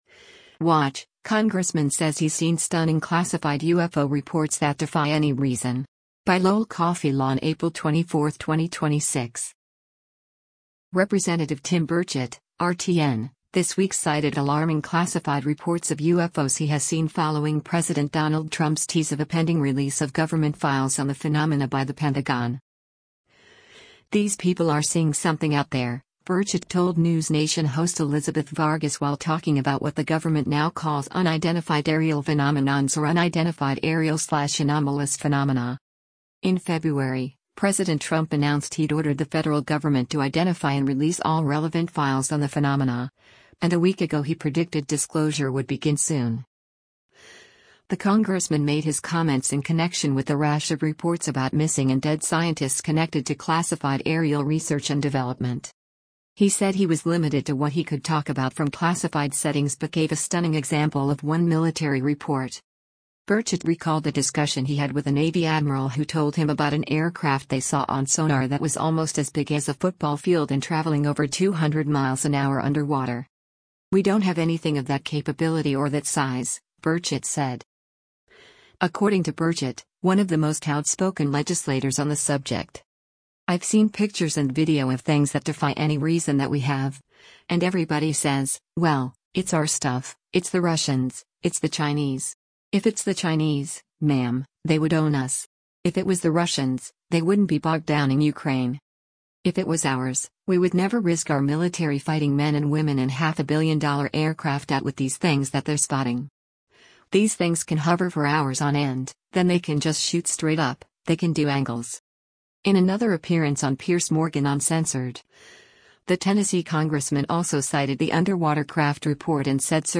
“These people are seeing something out there,” Burchett told News Nation host Elizabeth Vargas while talking about what the government now calls UAPs or “unidentified aerial/anomalous phenomena.”